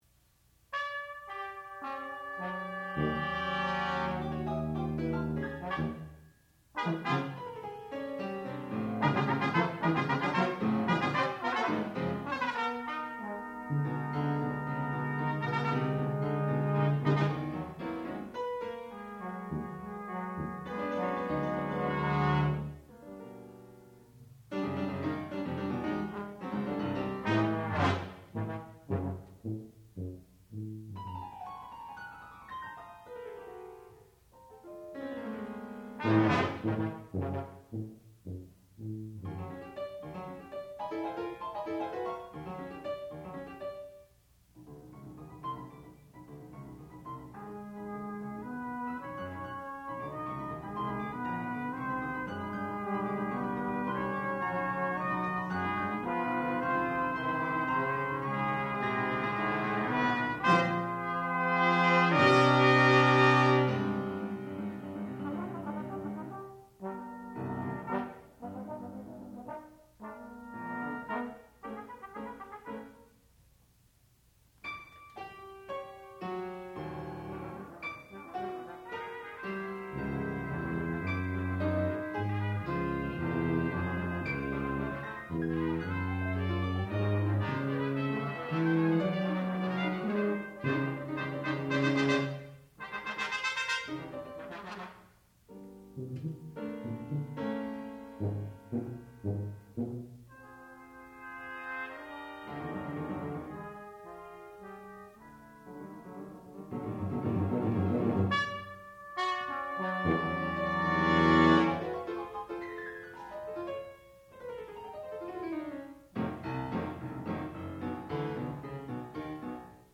sound recording-musical
classical music
Student Recital
piano